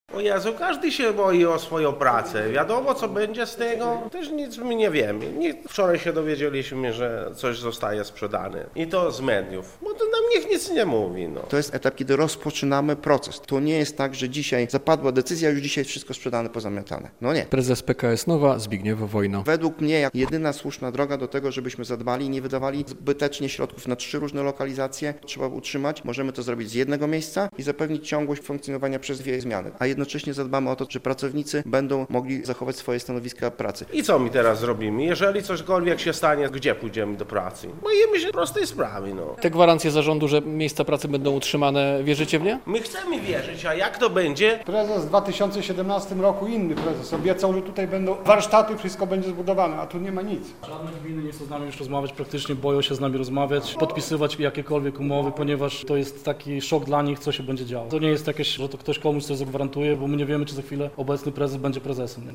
Zarząd PKS Nova zapowiada zmiany. W tle obawy o zwolnienia - relacja